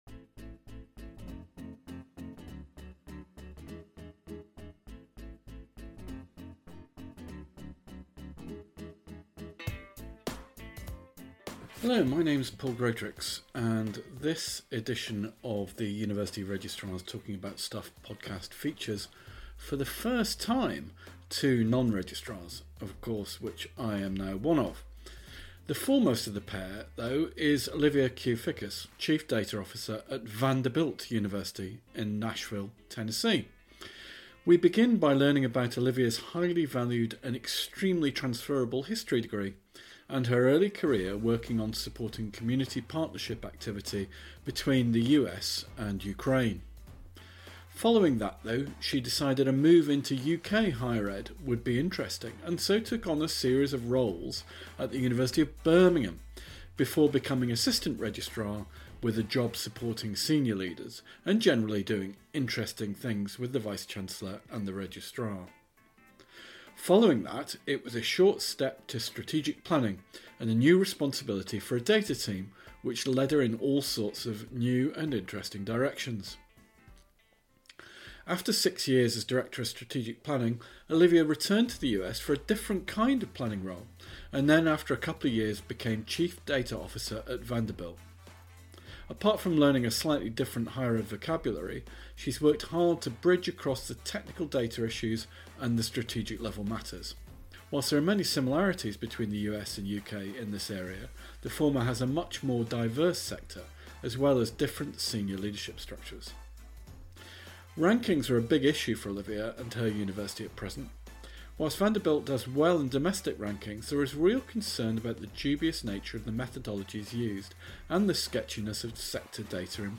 This edition of the podcast features, for the first time, two non-Registrars (of which I am of course now one).